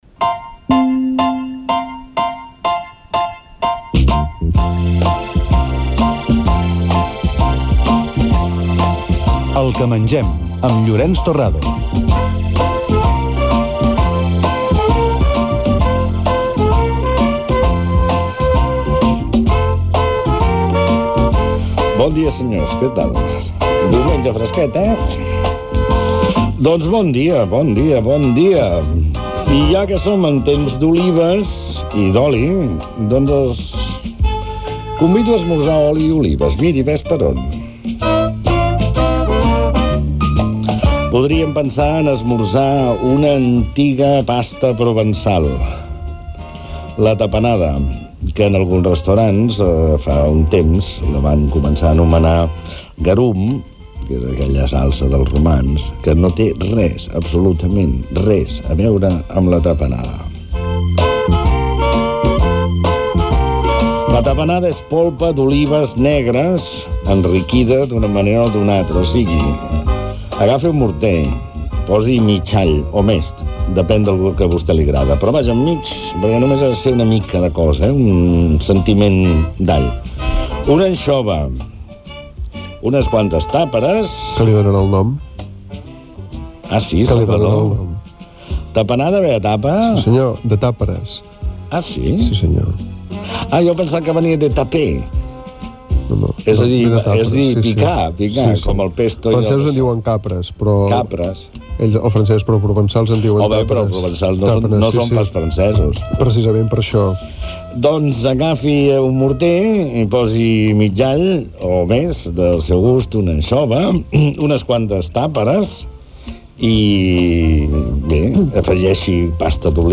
Careta del programa, presentació, comentari sobre la "tapanada".
Divulgació
FM